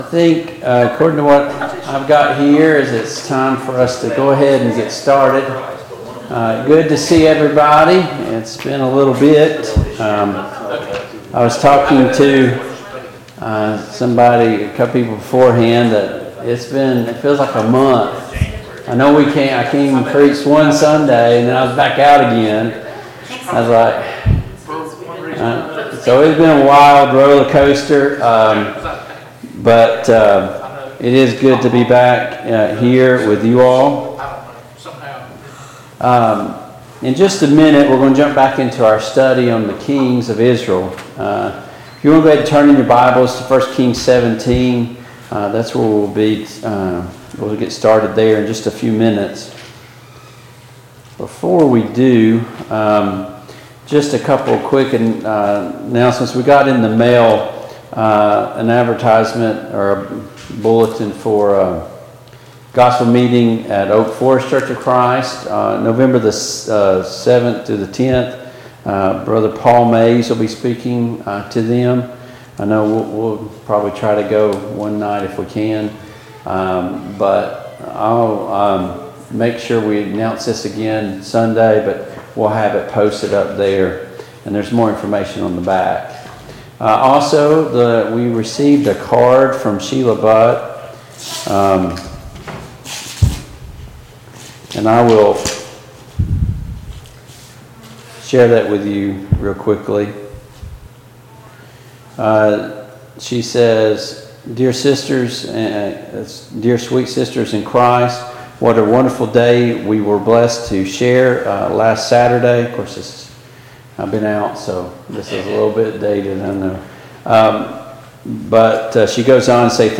Passage: I Samuel 17 Service Type: Mid-Week Bible Study